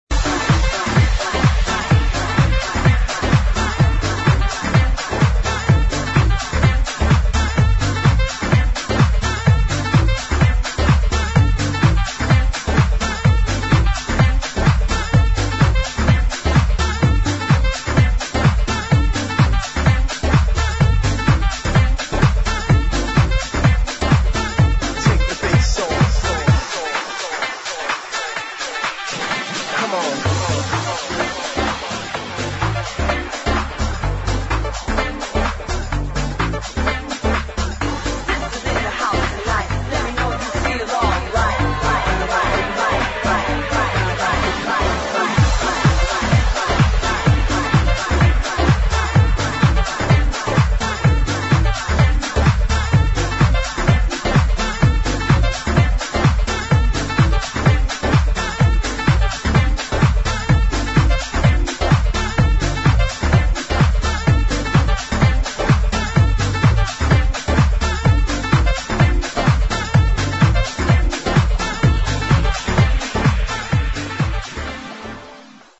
[ HOUSE | HIP HOUSE | FUNKY HOUSE ]